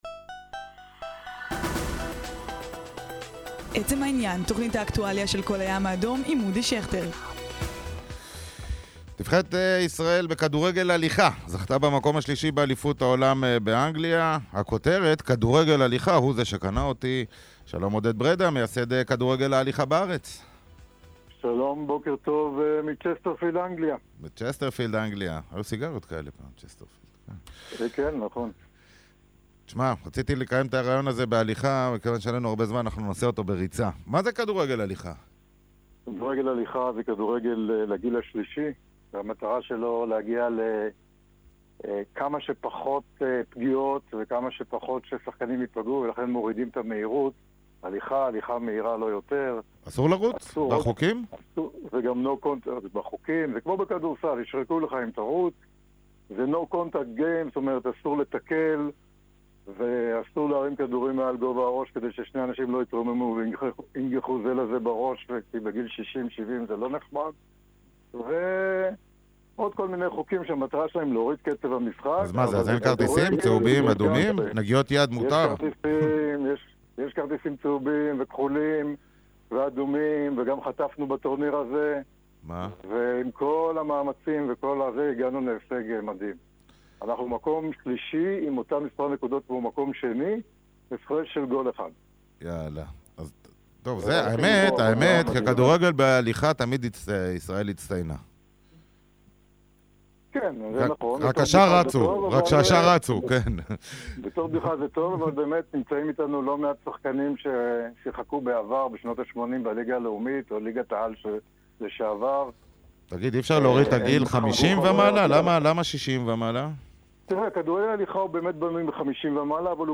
ראיון ברדיו על ההשתתפות באליפות העולם